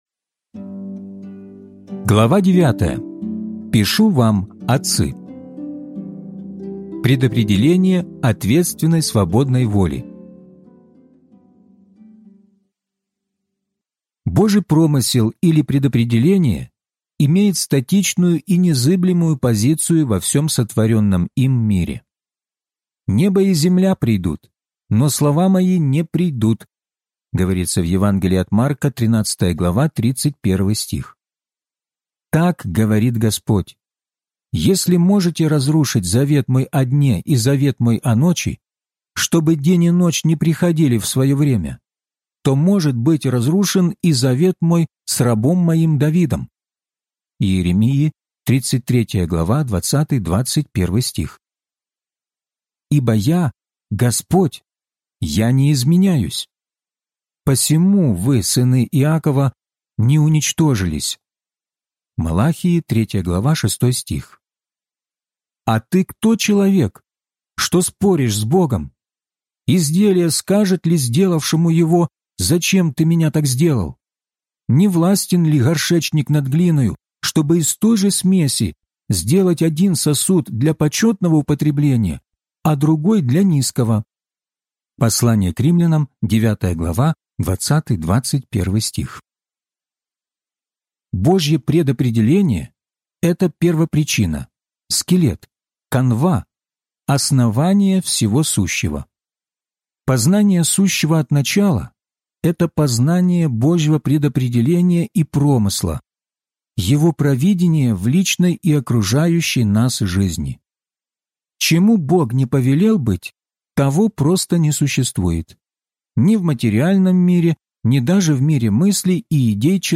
Возрастайте! (аудиокнига) - День 25 из 34